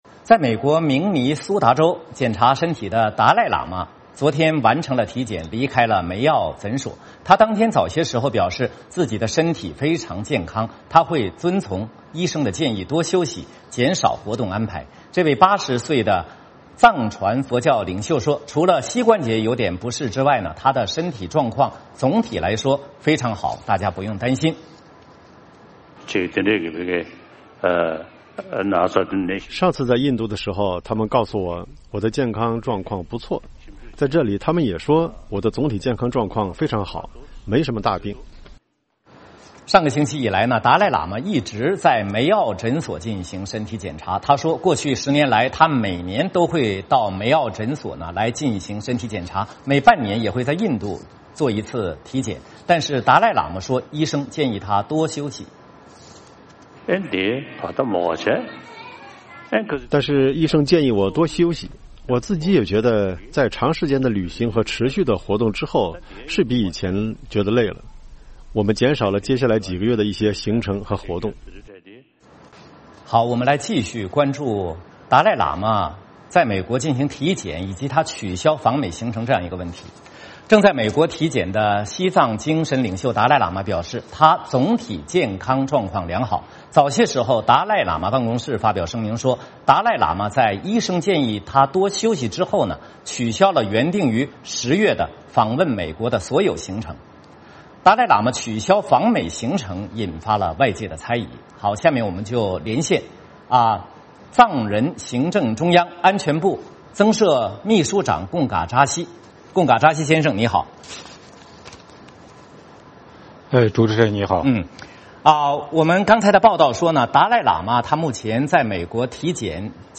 VOA连线：达赖喇嘛为何取消访美公开活动？